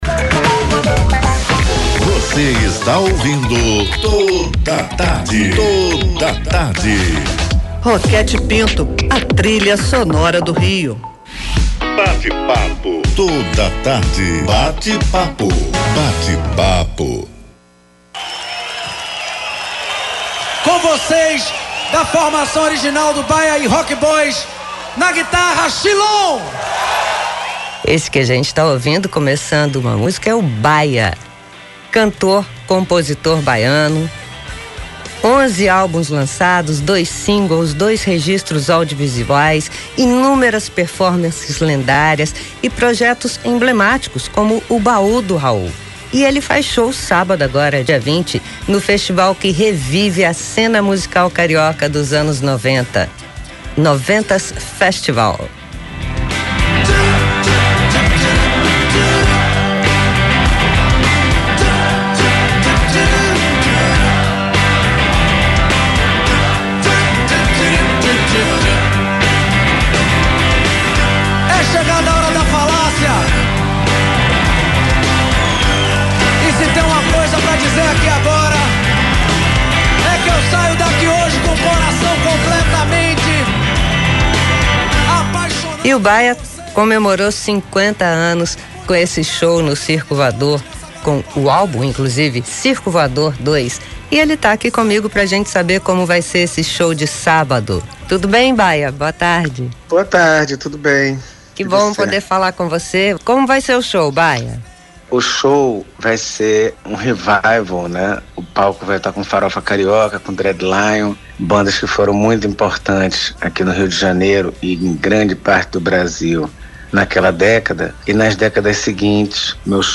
No Bate-Papo do "Toda Tarde"